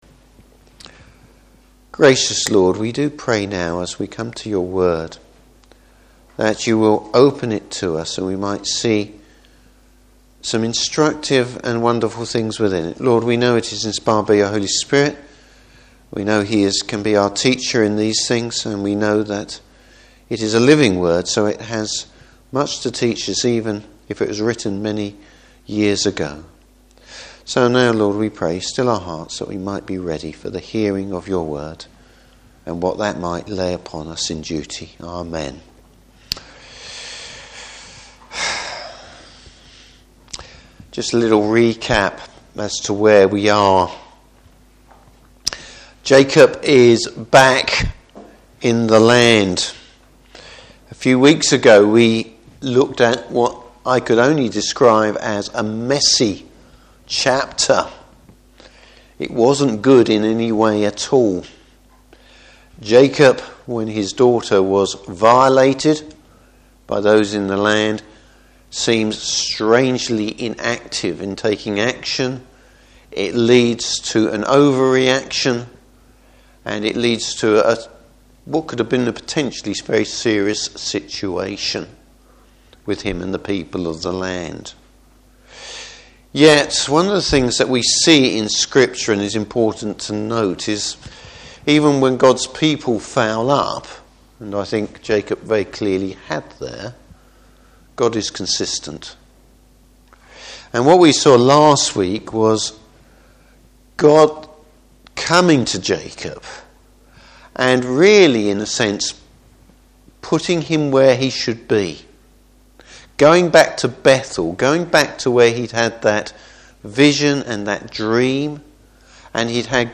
Service Type: Evening Service A picture of God at work even if we’re not aware of it.